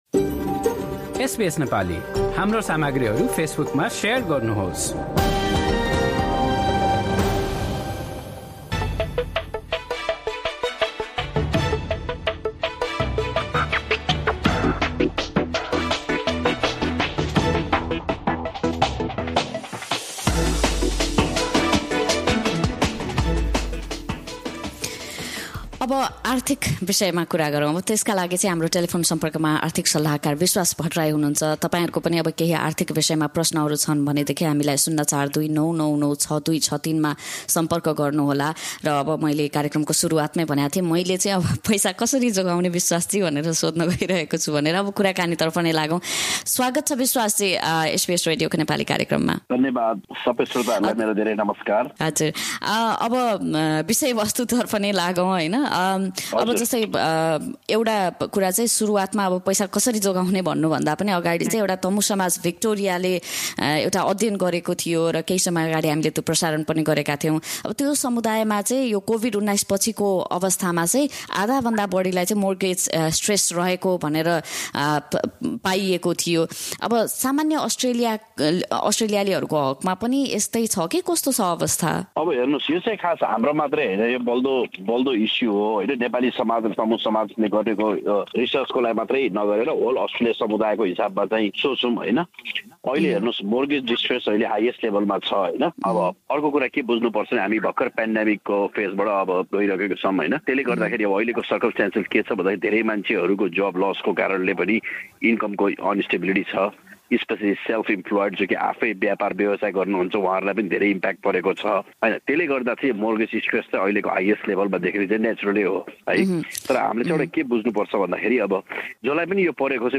आर्थिक कुराकानी हरेक महिनाको पहिलो आइतवार अपराह्न ४ बजेको कार्यक्रममा प्रत्यक्ष प्रसारण हुन्छ।